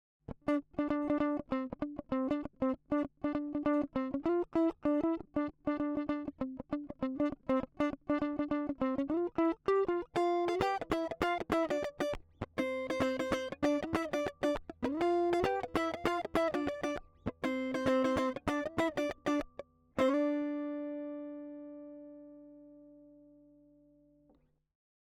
今回録音して頂いた、音源サンプルでは、入力のゲインを上げ気味で、出力で下げるという使い方での録音もして頂きました。
やりすぎると歪んじゃいますけど、歪むギリギリのところというか、若干サチュレーションがかかるところがNeveのよさで、気持ちいいところだったりしますね。